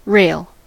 rail: Wikimedia Commons US English Pronunciations
En-us-rail.WAV